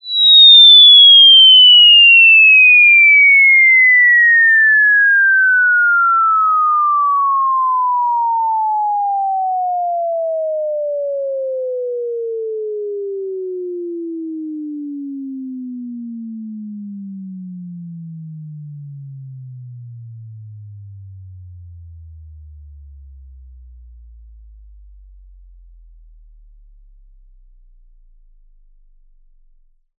test_invsweep.wav